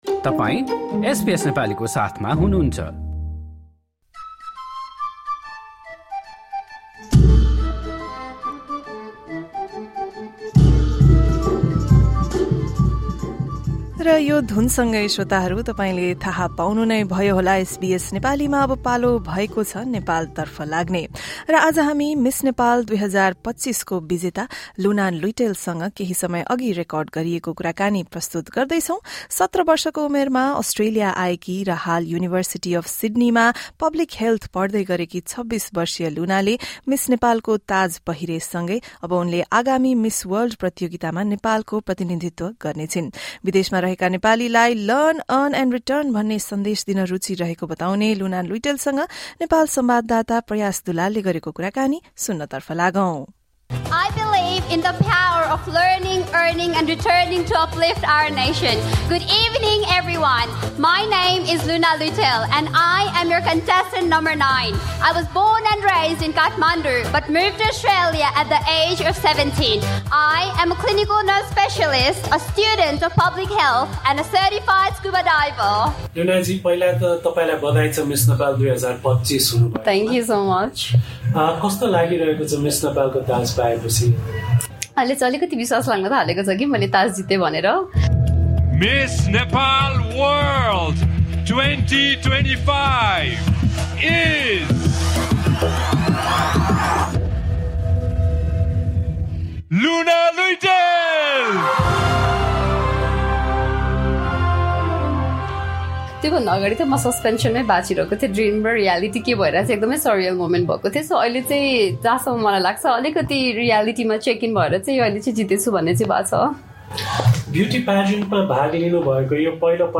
पोडकास्टको यो अङ्कमा हामी मिस नेपाल २०२५की विजेता लुना लुइँटेलसँग केही समय अघि रेकर्ड गरिएको कुराकानी प्रस्तुत गर्दै छौँ। सत्र वर्षको उमेरमा अस्ट्रेलिया आएकी र हाल युनिभर्सिटी अफ सिड्नीमा पब्लिक हेल्थ पढ्दै गरेकी २६ वर्षीय लुइँटेलले मिस नेपालको ताज पहिरेसँगै अब उनले आगामी मिस वर्ल्ड प्रतियोगितामा नेपालको प्रतिनिधित्व गर्ने छिन्।